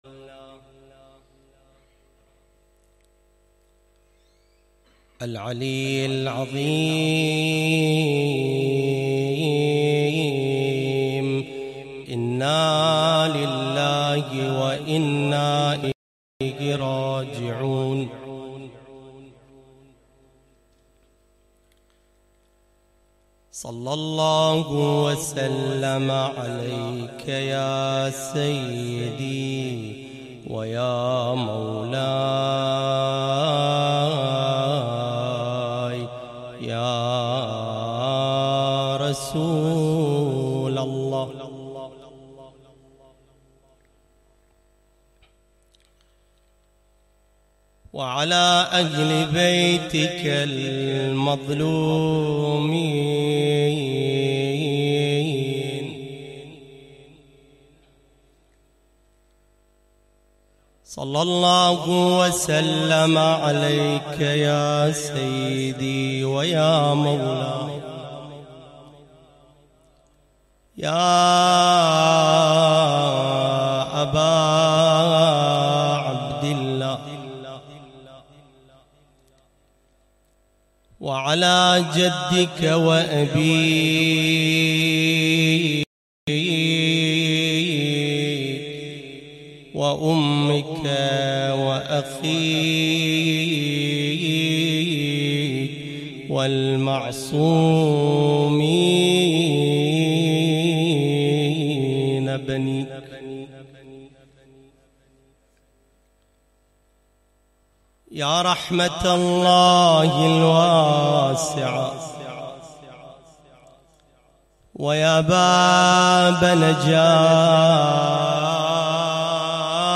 المجلس الحسيني
محاضرة